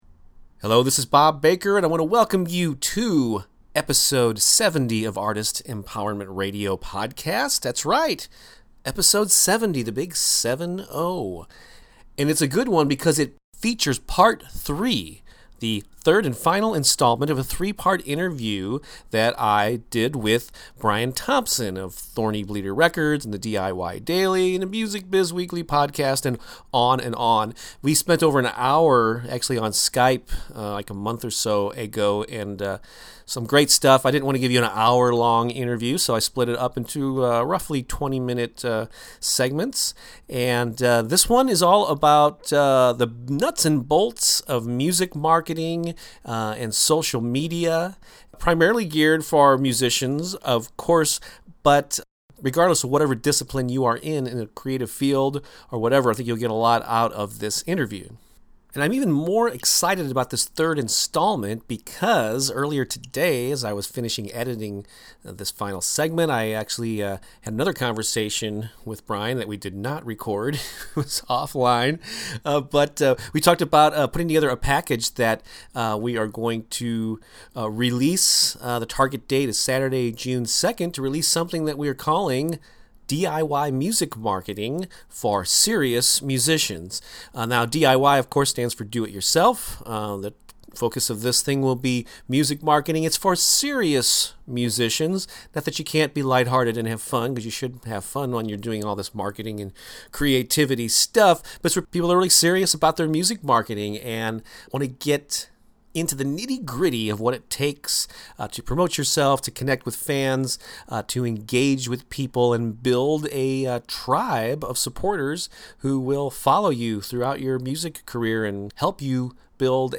In this third and final interview segment
The music at the beginning and end of the episode